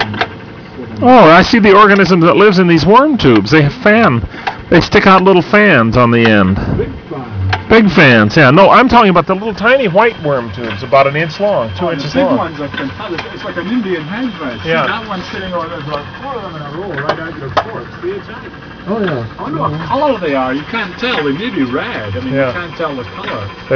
From inside Alvin